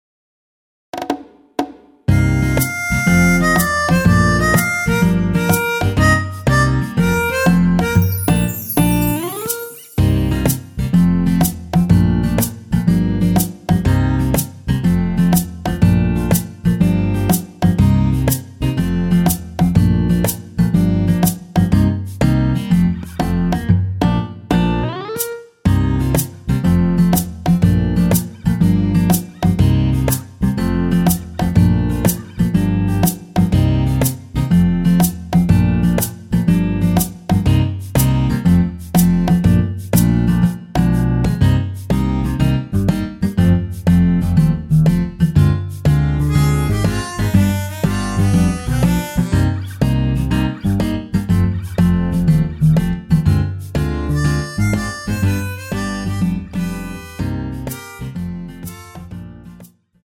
Bb
음정은 반음정씩 변하게 되며 노래방도 마찬가지로 반음정씩 변하게 됩니다.
앞부분30초, 뒷부분30초씩 편집해서 올려 드리고 있습니다.
중간에 음이 끈어지고 다시 나오는 이유는